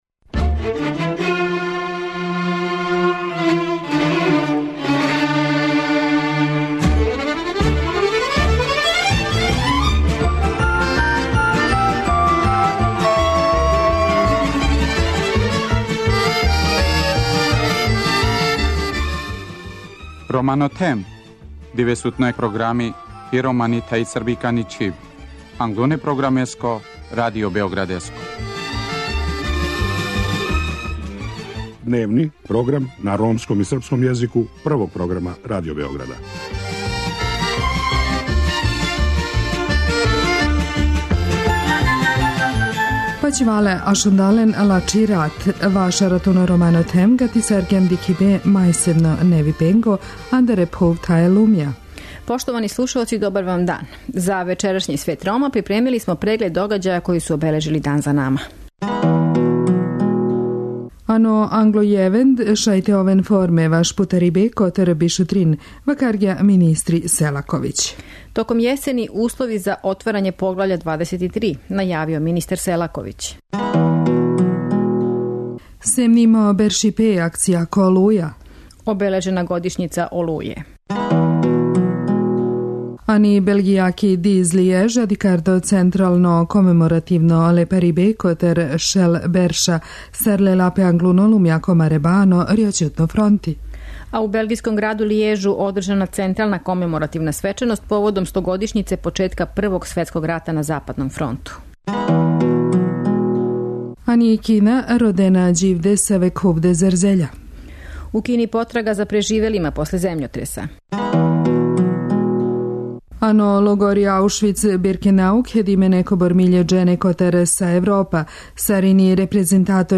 У логору Аушвиц-Биркенау окупило се неколико хиљада људи из читаве Европе, као и представници великог броја влада европских земаља како би обележили 70 година страдања Рома у овом стратишту. У вечерашњој емисији припремили смо извештај са овога догађаја.